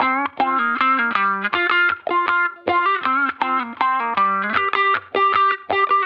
Index of /musicradar/sampled-funk-soul-samples/79bpm/Guitar
SSF_StratGuitarProc1_79G.wav